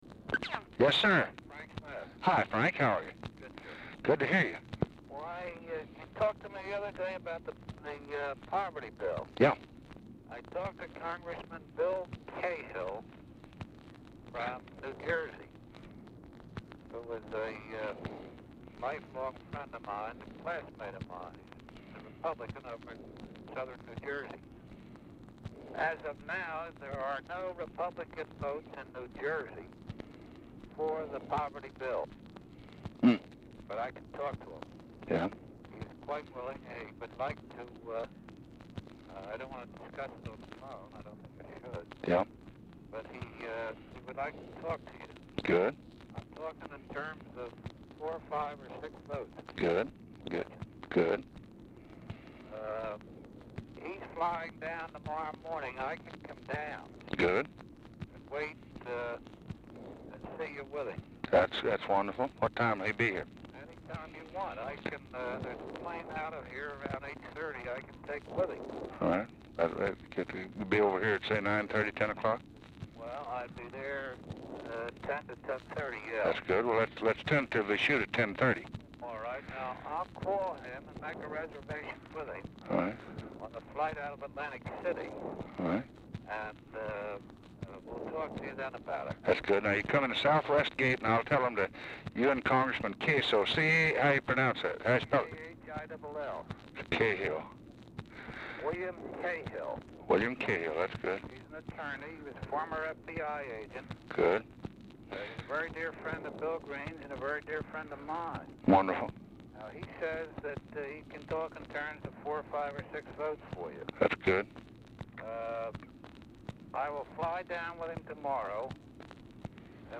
Telephone conversation
Format Dictation belt
Location Of Speaker 1 Mansion, White House, Washington, DC